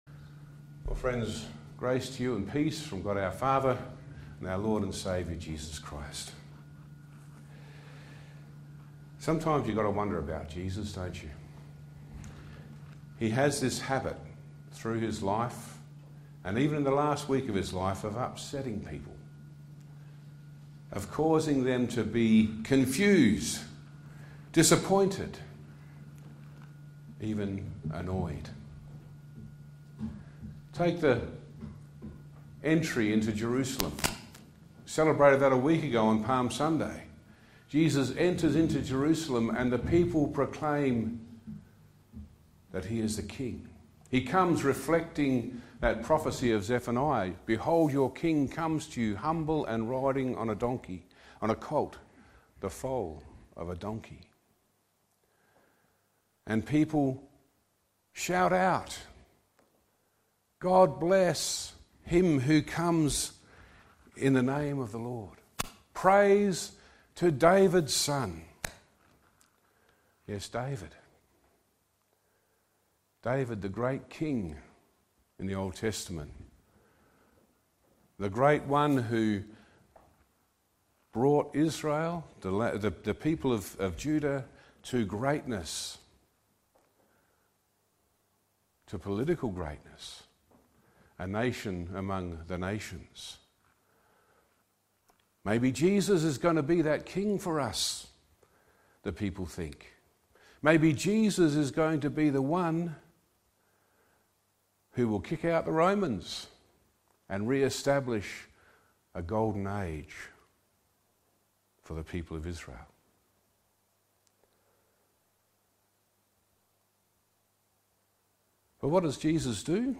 Sermons Online Audio Friday 3 Apr Good Friday